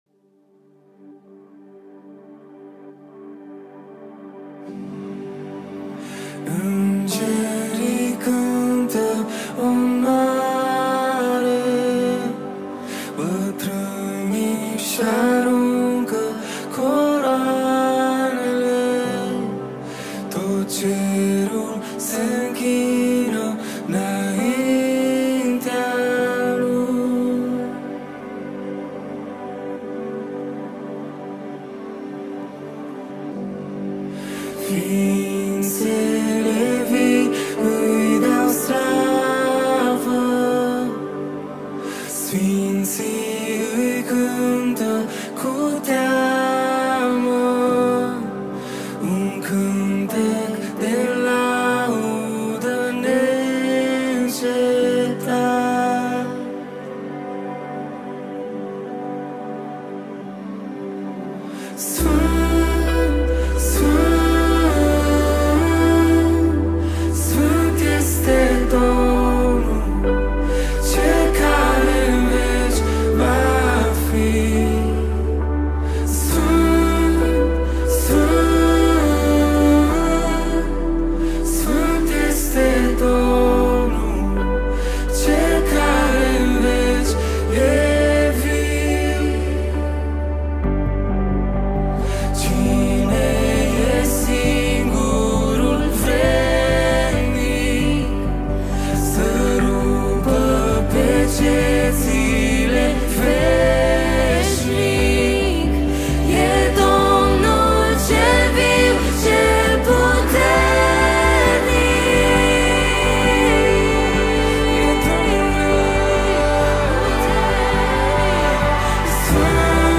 710 просмотров 104 прослушивания 6 скачиваний BPM: 67